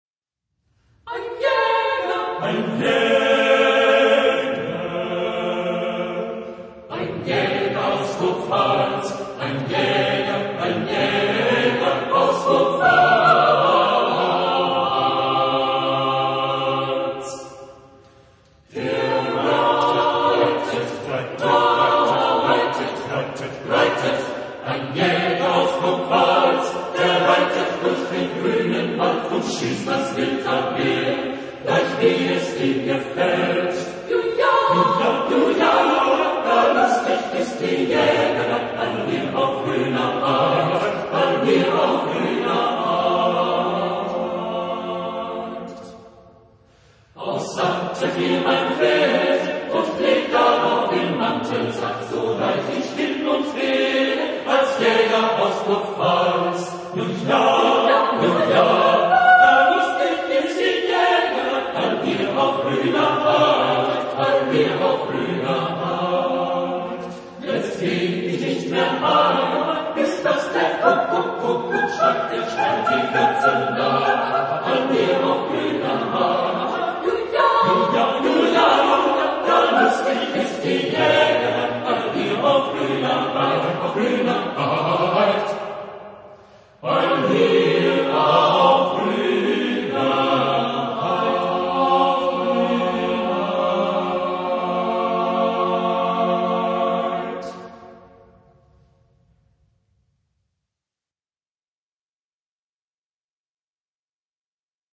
Genre-Stil-Form: Volkslied ; Liedsatz ; weltlich
Chorgattung: SATB  (4 gemischter Chor Stimmen )
Tonart(en): F-Dur